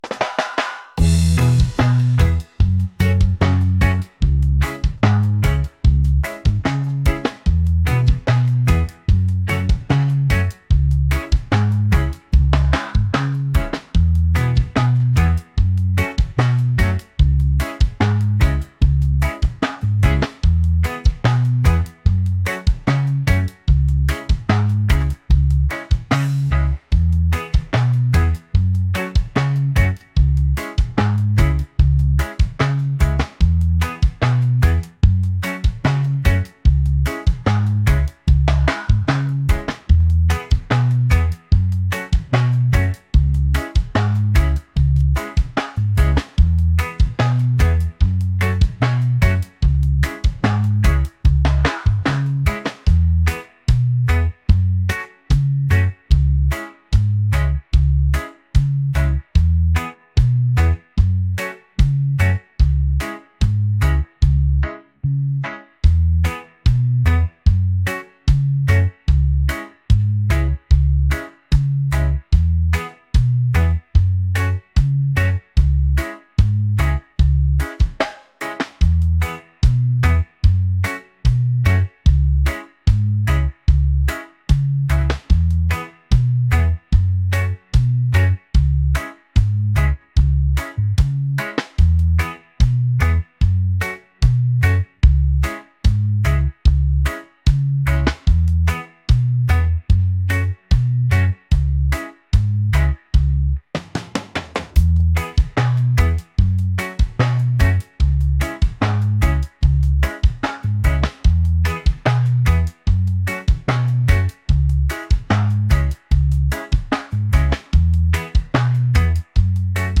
laid-back | reggae